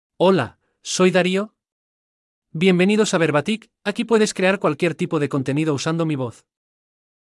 DarioMale Spanish AI voice
Dario is a male AI voice for Spanish (Spain).
Voice sample
Dario delivers clear pronunciation with authentic Spain Spanish intonation, making your content sound professionally produced.